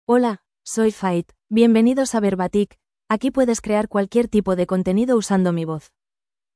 Faith — Female Spanish (Spain) AI Voice | TTS, Voice Cloning & Video | Verbatik AI
Faith is a female AI voice for Spanish (Spain).
Voice sample
Listen to Faith's female Spanish voice.
Faith delivers clear pronunciation with authentic Spain Spanish intonation, making your content sound professionally produced.